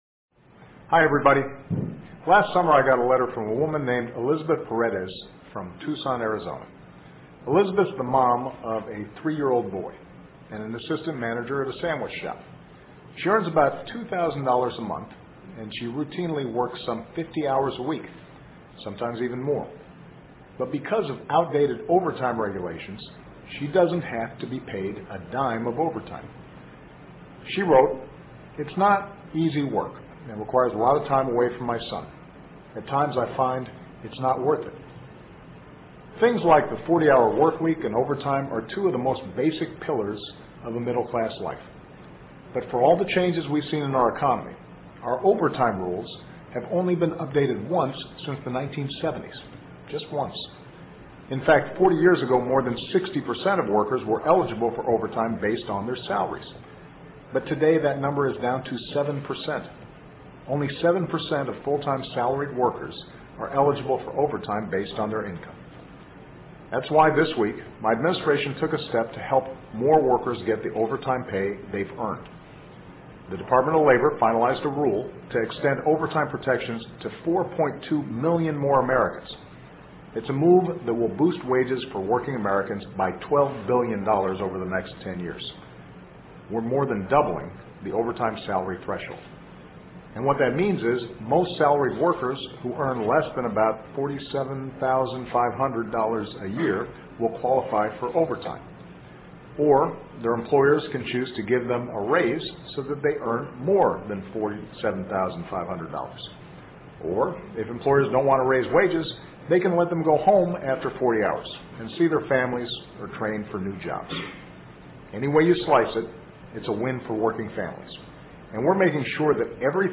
奥巴马每周电视讲话：总统呼吁保护中低收入者！推加班费新规受惠人群将达 听力文件下载—在线英语听力室